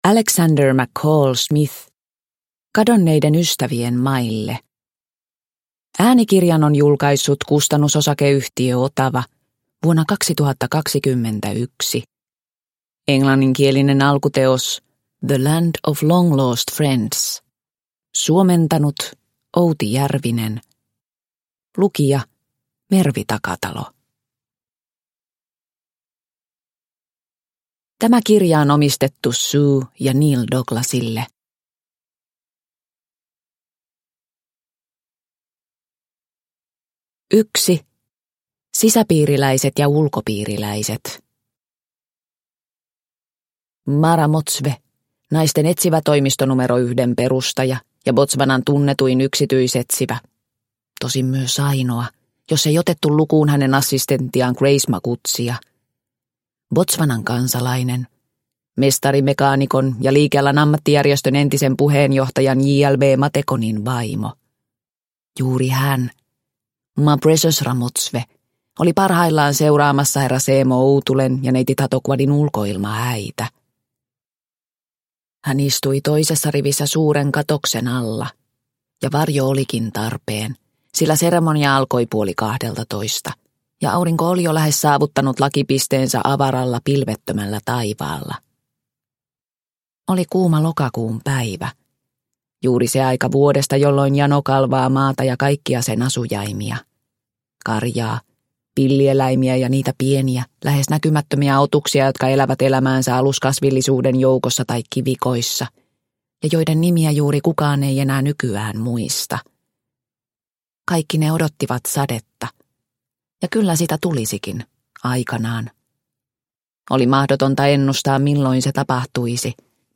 Kadonneiden ystävien maille – Ljudbok – Laddas ner